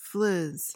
PRONUNCIATION: (fliz) MEANING: noun: Something existing only in name: an illusion or empty semblance.